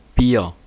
Ääntäminen
IPA: [biːɐ̯] IPA: /biːə/